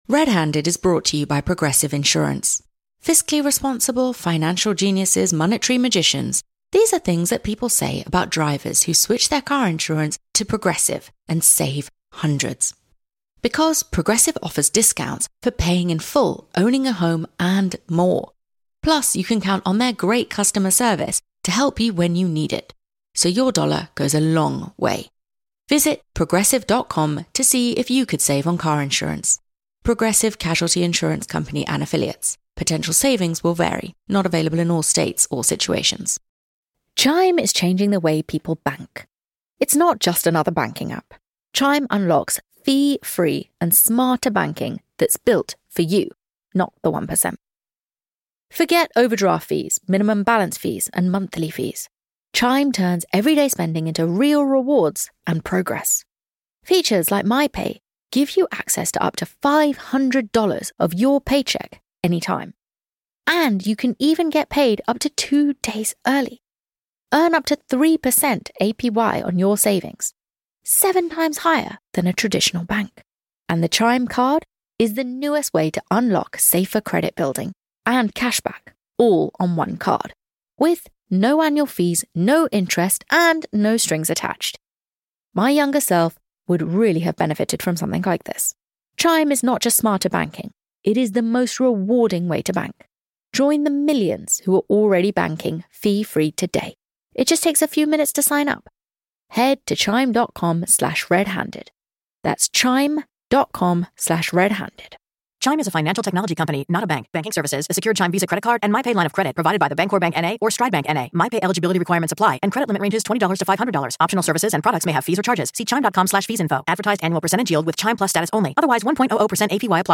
Director Joe Berlinger on Ted Bundy: Live
Extremely Wicked, Shockingly Evil and Vile is the new Ted Bundy film; in this very special episode of RedHanded Joe Berlinger, the director, joins the girls for an exclusive Q&A to delve into the psychology of Ted Bundy.